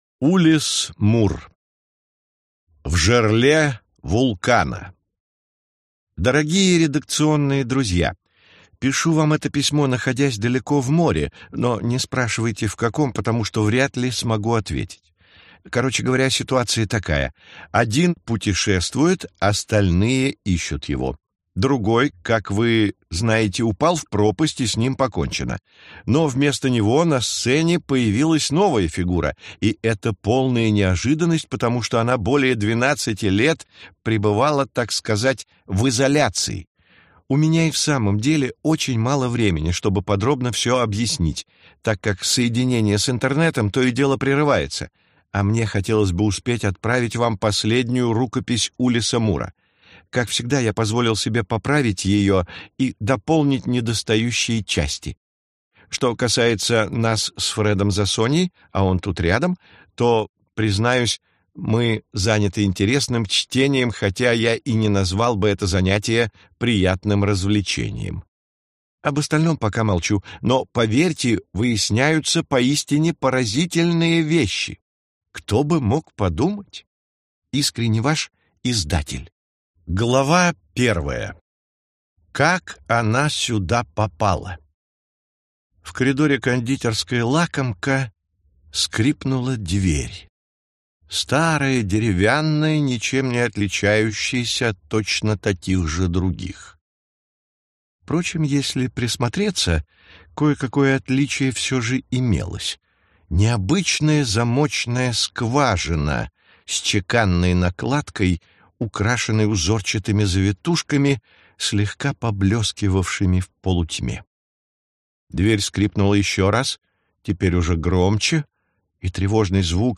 Аудиокнига В жерле вулкана | Библиотека аудиокниг